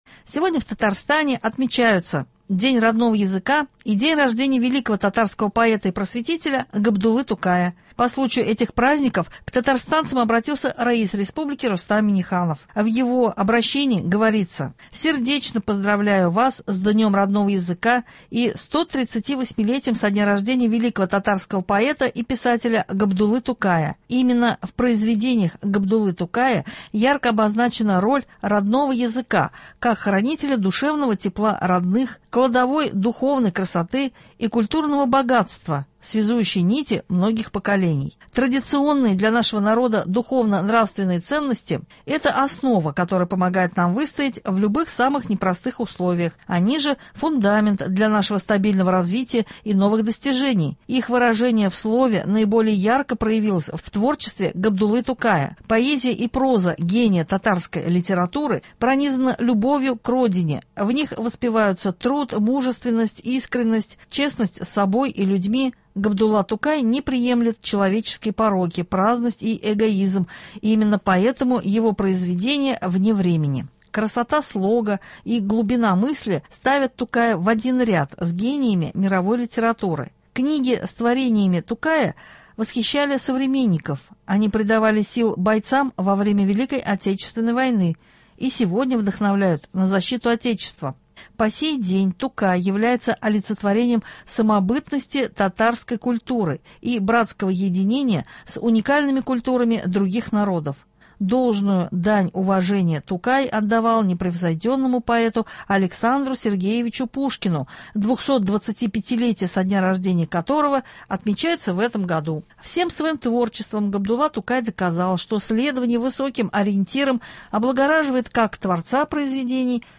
Новости (26.04.24)